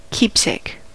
keepsake: Wikimedia Commons US English Pronunciations
En-us-keepsake.WAV